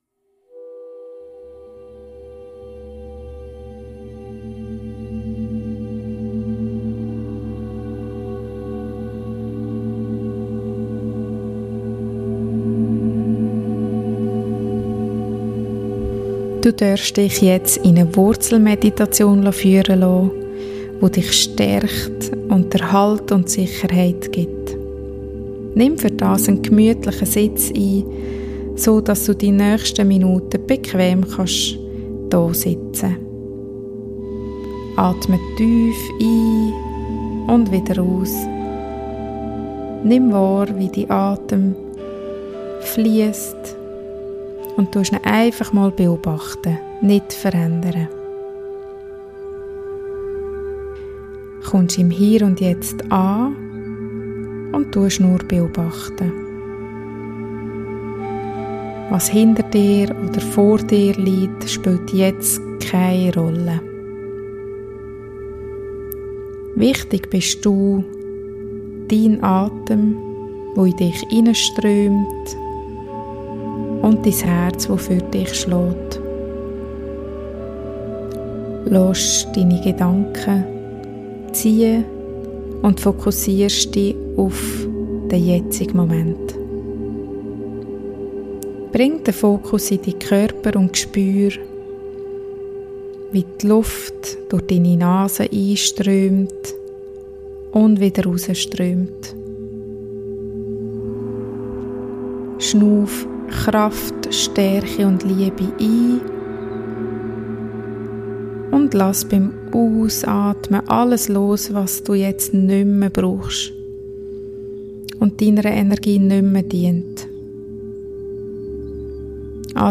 Beschreibung vor 6 Monaten In dieser Folge erlebst du eine geführte Meditation, um dein Wurzelchakra zu stärken um mehr Stabilität und Kraft zu schöpfen.